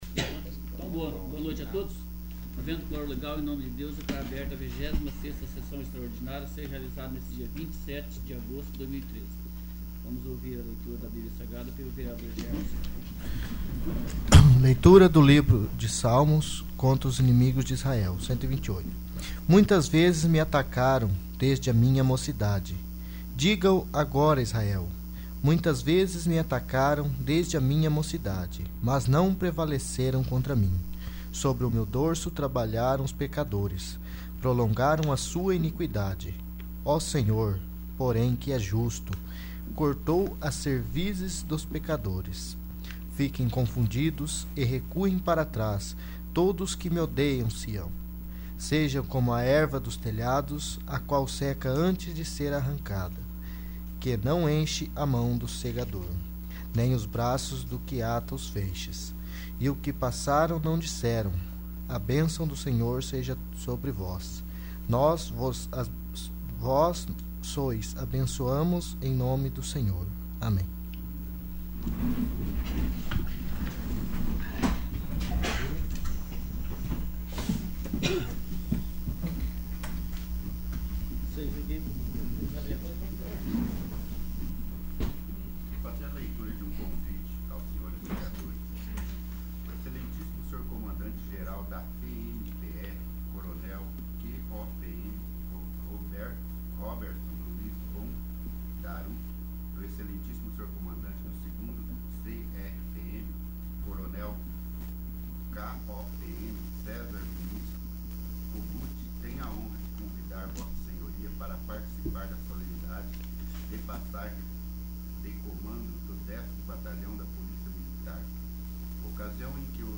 25º. Sessão Extraordinária